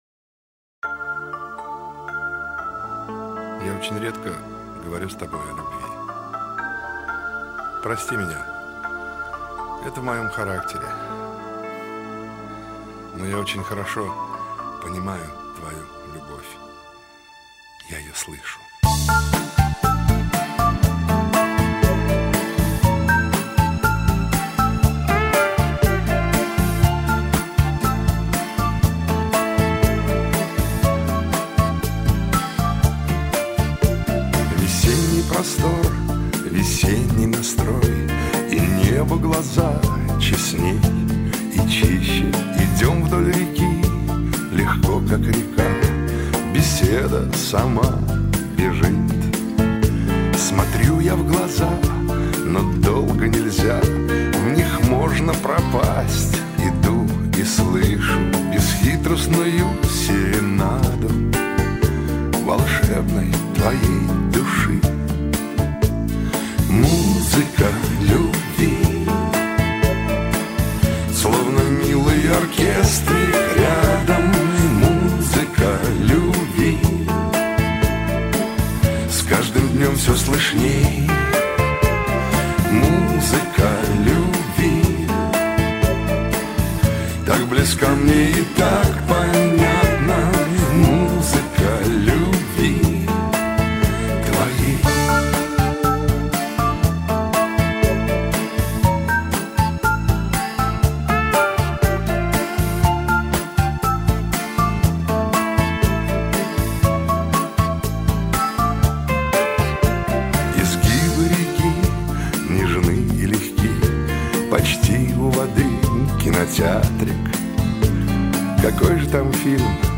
您当前位置：网站首页 > 香颂（шансон）界
非常浪漫柔和的轻音乐。以轻柔优雅的旋律和感性的歌声来描述罗曼蒂克的唯美场景。